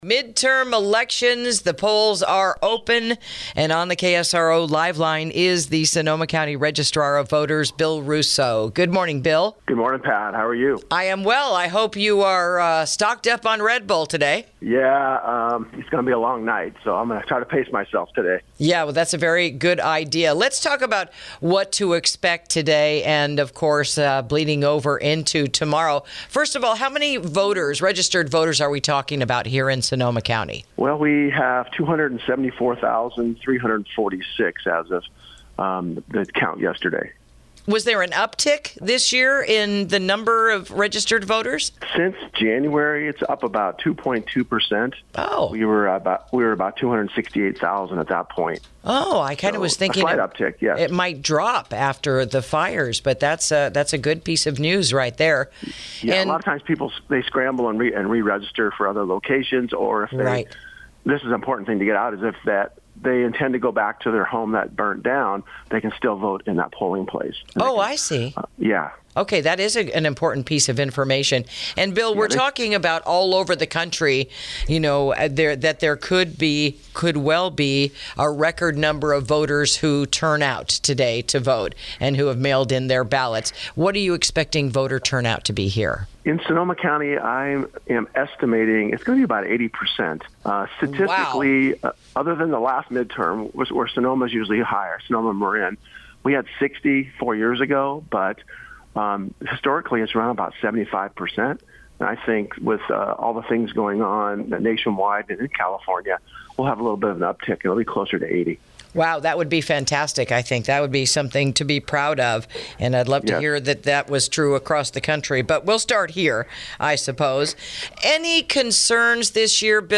Clerk, recorder and assessor for the Sonoma County Registrar of Voters, Bill Rousseau, shares how many registered voters we have in Sonoma County as of the count yesterday, what he expects voter turnout to be, whether he has any concern when it comes to fraud in this year’s local election, and how many mail-in-ballots have already been turned in: